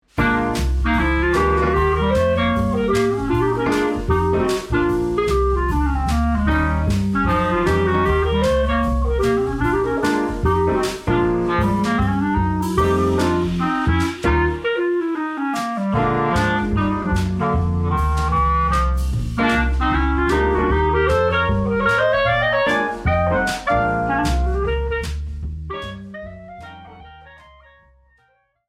with a swinging twist